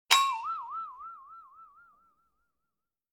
Funny Boing Flexatone Wobble - Bouton d'effet sonore